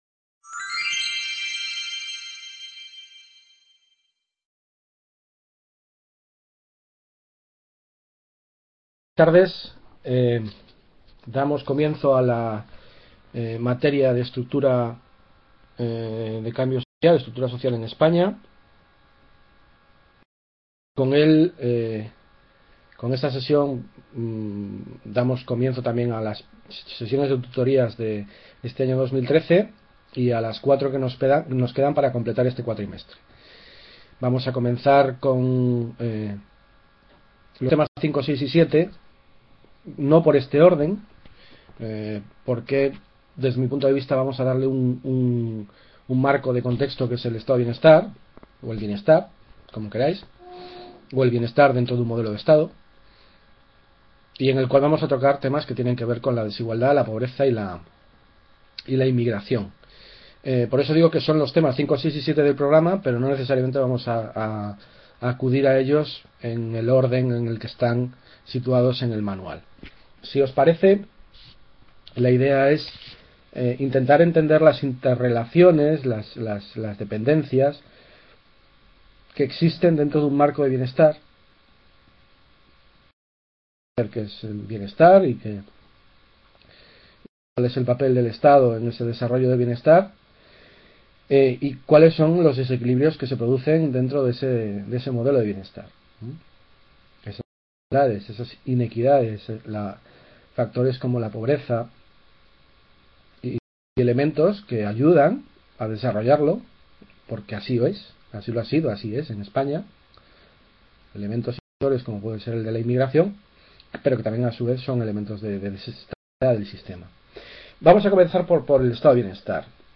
Webconferencia: Bienestar, Desigualdad y Pobreza,…